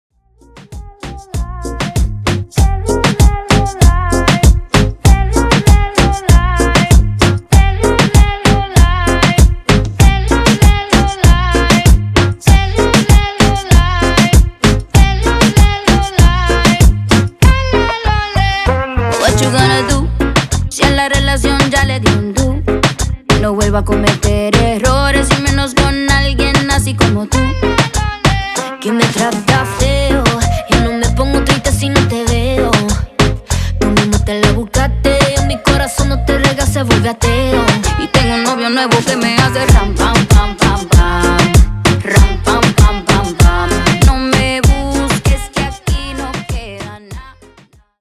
Genre: MASHUPS Version: Clean BPM: 102 Time